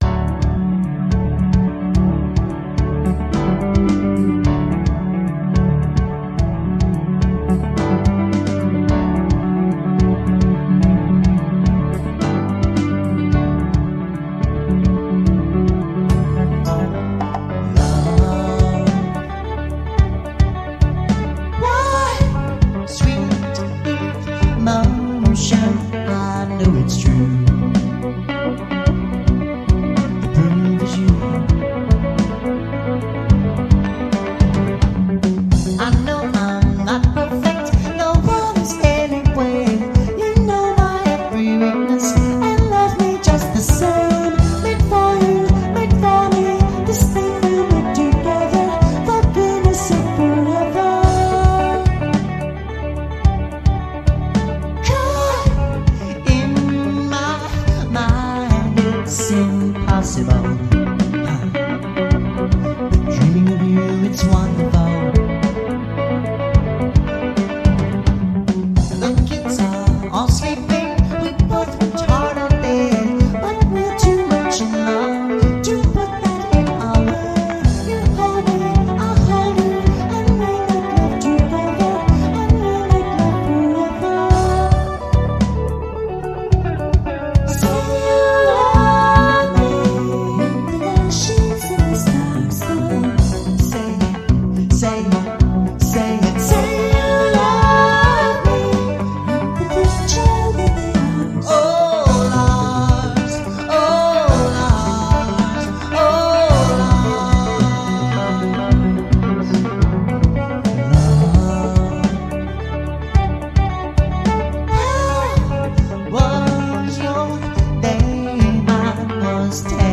writing musically adventurous songs in a genre that seemed to blend jazz fusion complexity with new age optimism
There are so many moments of musical magic here, from the quite original drum pattern that opens and closes the song, to the echo-effect guitar that vaguely trails the vocal melody, to the little vocal exhalation that follows the line “caught in my mind, it’s impossible”, to the variety of keyboard sounds that begin to inhabit the corners of the song shortly after the first chorus, playing lines of such subtlety I only discerned some of them for the first time on recent re-listening.
Yes, it’s a muddy old analog recording. Yes, it might sound dated to your ears (although not to mine–I’ll admit to a complete lack of objectivity here.)